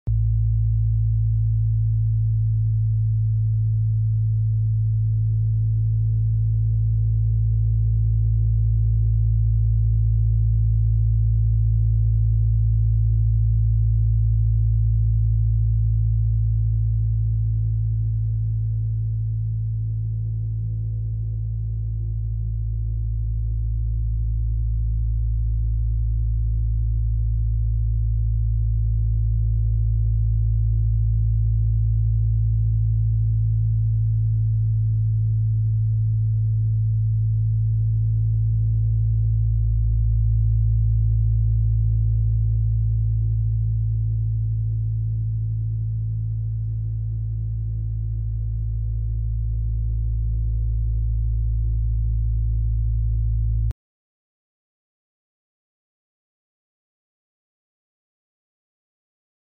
Frequência Binaural 56 Hz sound effects free download
Frequência Binaural 56 Hz - Delta